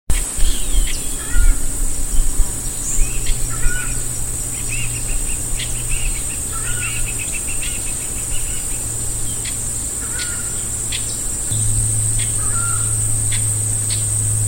Tachã (Chauna torquata)
Nome em Inglês: Southern Screamer
Localidade ou área protegida: Reserva Natural del Pilar
Condição: Selvagem
Certeza: Gravado Vocal